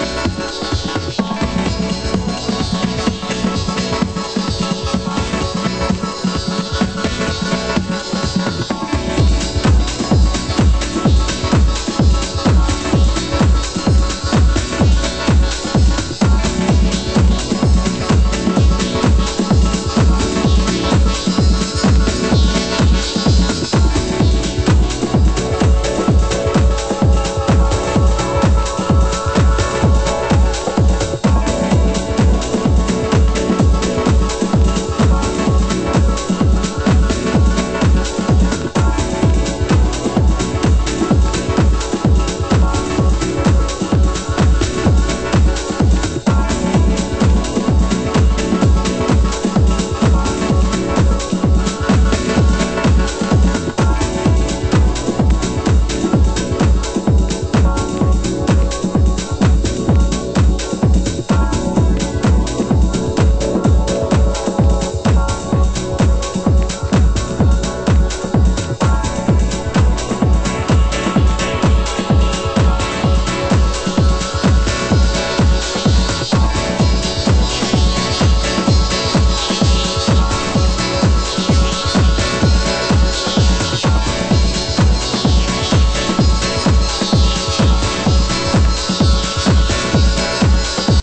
盤質：A面全編に掛かる線の傷ノイズ 有/ノイズ多し（試聴でご確認ください）